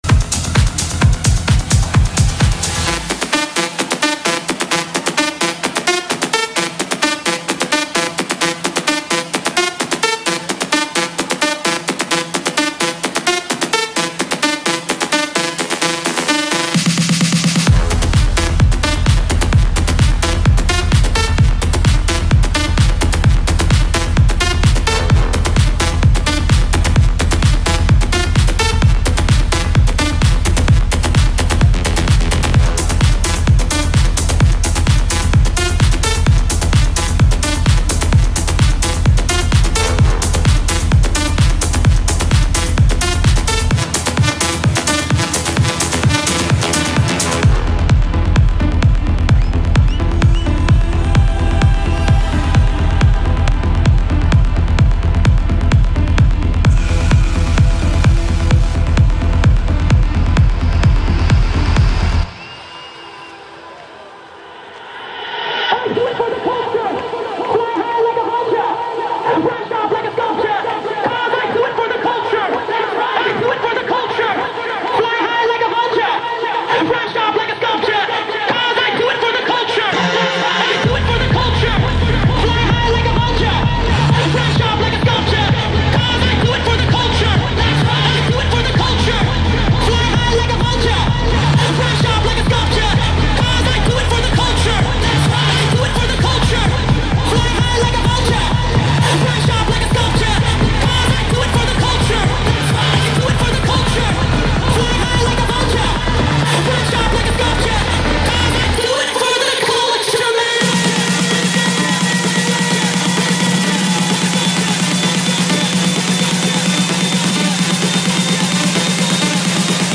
Also find other EDM Livesets, DJ Mixes and Radio